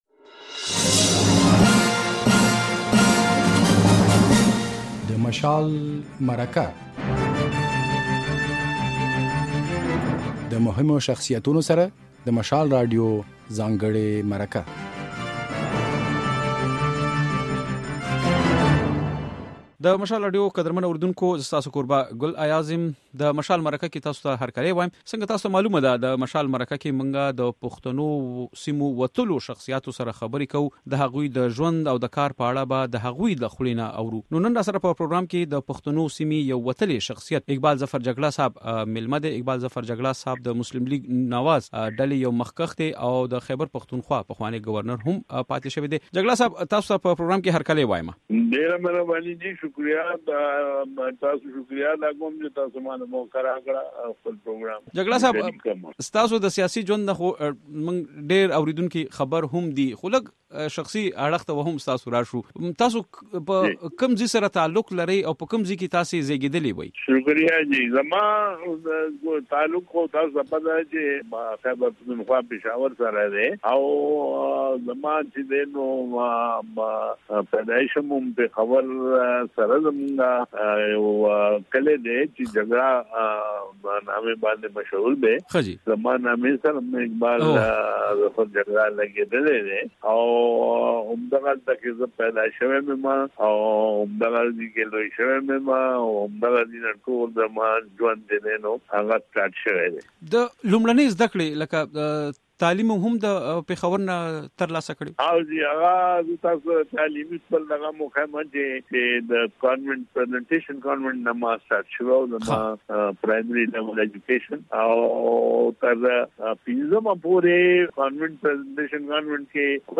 د مشال مرکه کې مو د خيبر پښتونخوا پخوانی ګورنر اقبال ظفر جګړا مېلمه دی. په خپرونه کې له نوموړي سره د قبايلي ضلعو په صوبه کې د شاملولو پر موضوع خبرې شوې دي. جګړا وايي، په پښتونخوا یې د قبايلي ضلعو د ورګډولو لپاره ډېر کار کړي وو.